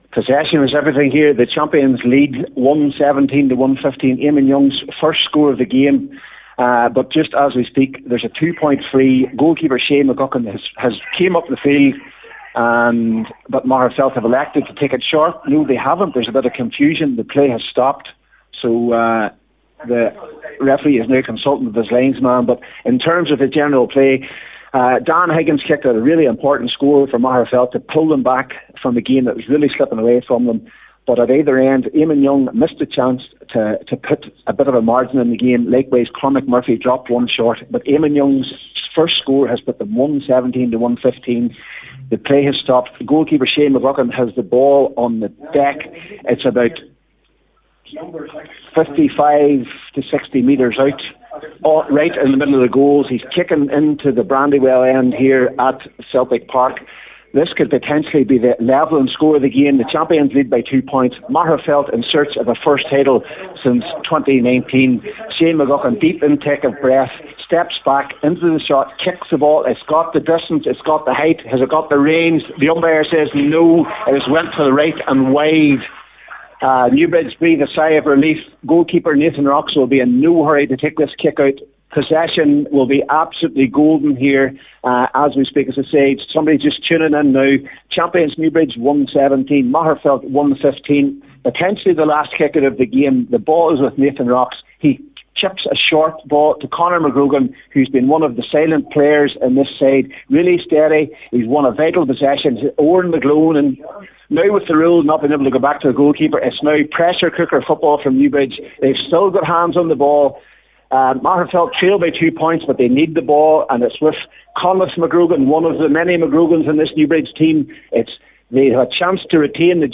was live on Highland Radio Sunday Sport as the full time whistle approached…